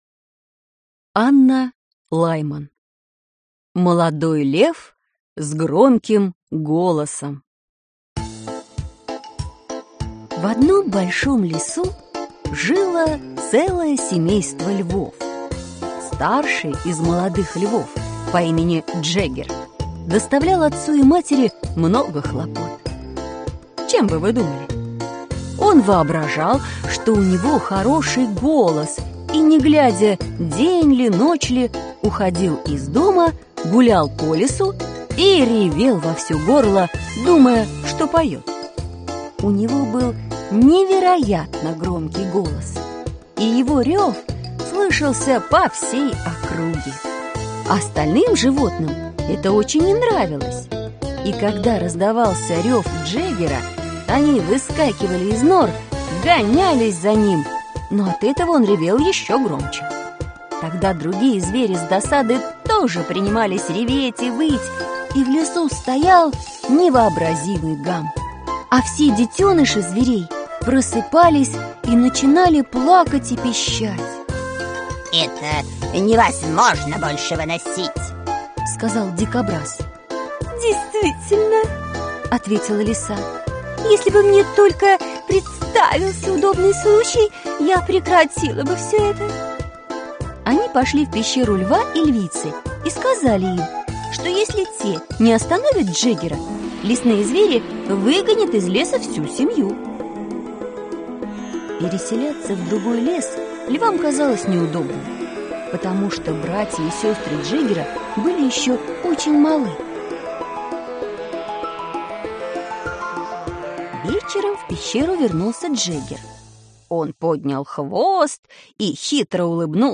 Аудиокнига Сказки английских писателей. Слонёнок | Библиотека аудиокниг